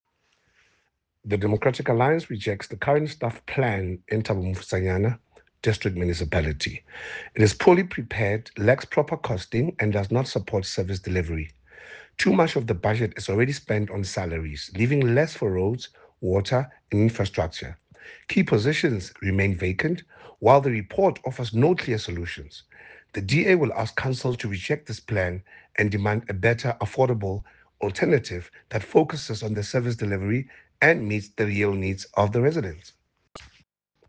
Sesotho soundbites by Cllr Eric Motloung and